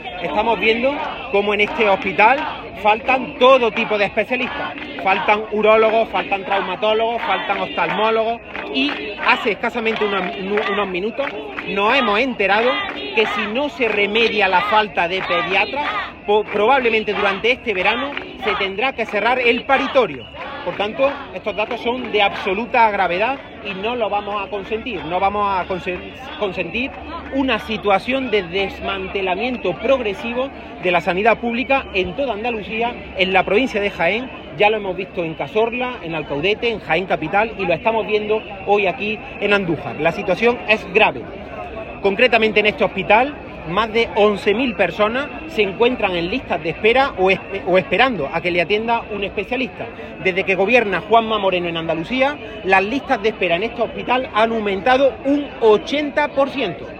Concentración de protesta contra la Junta por el desmantelamiento del centro hospitalario
El responsable socialista ha hecho estas declaraciones en la concentración de protesta celebrada junto al centro hospitalario en la que ha participado en su doble condición de alcalde de Arjona y secretario general del PSOE de Jaén.
Cortes de sonido